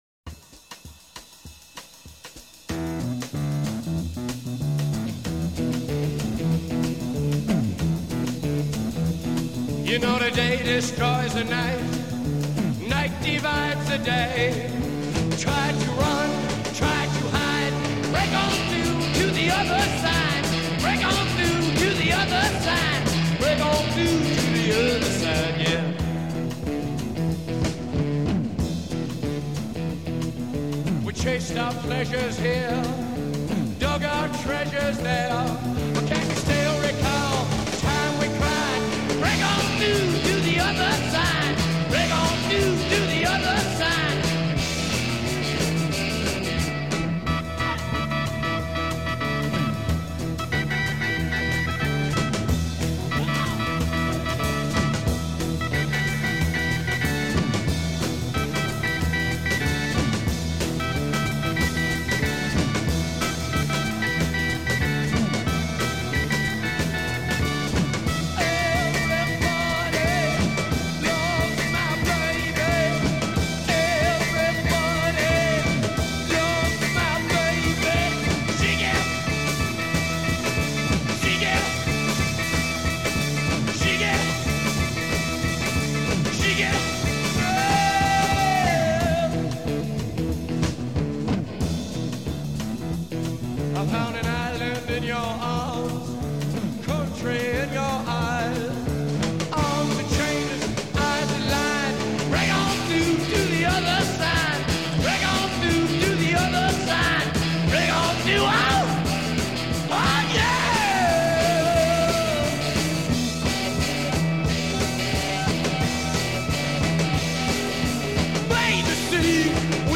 La canzone si apre con una ritmica jazz in 4/4